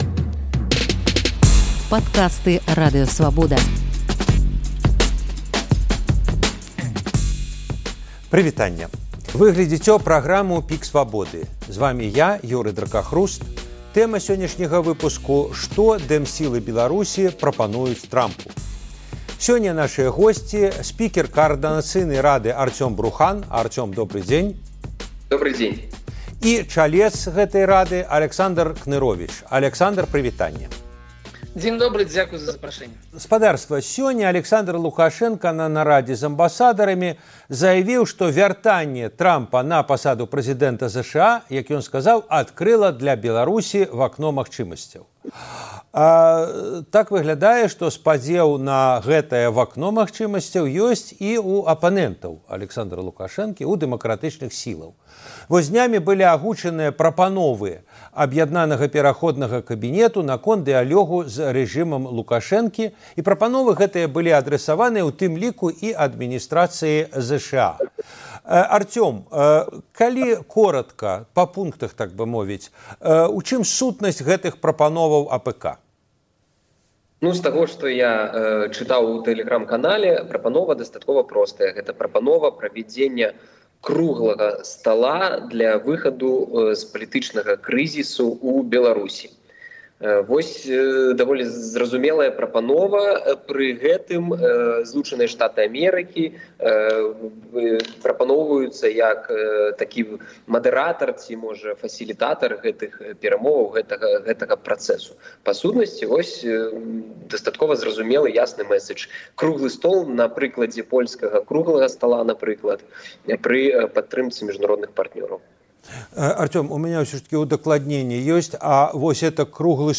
дыскусія